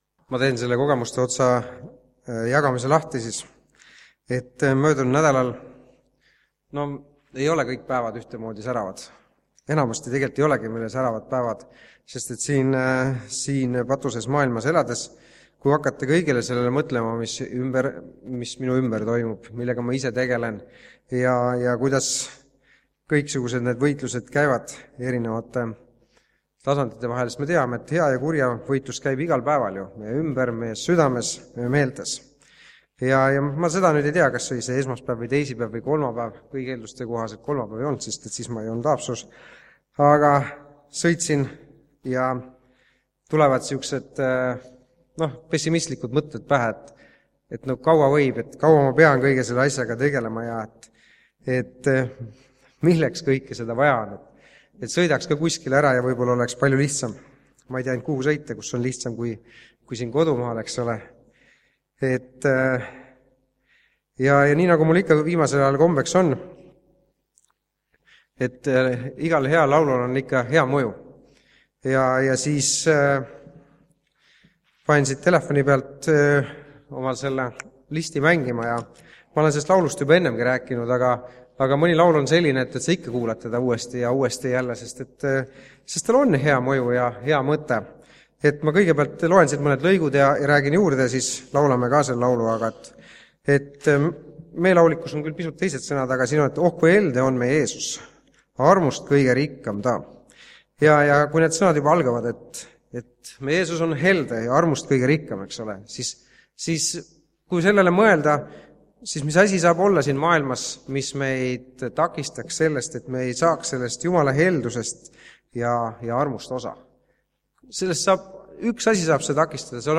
Jutlused
Täna on tunnistuste koosolek.